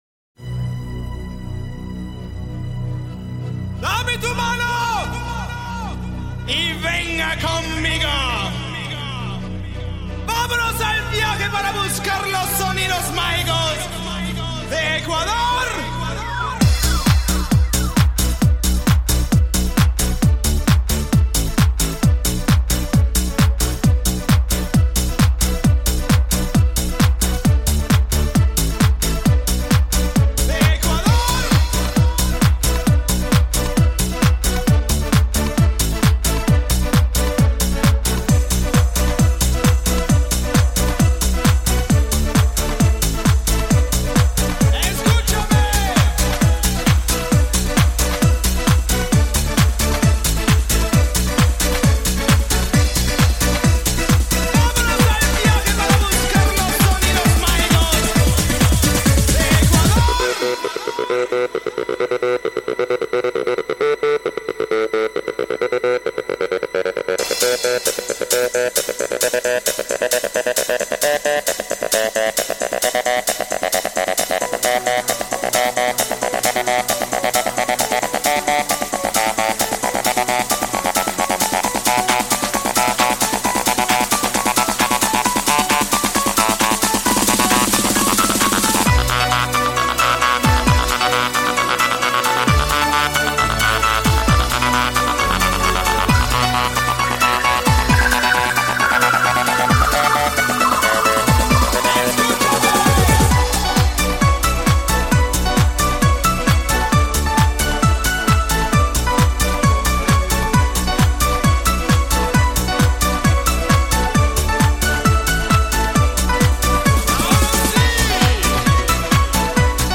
Жанр: House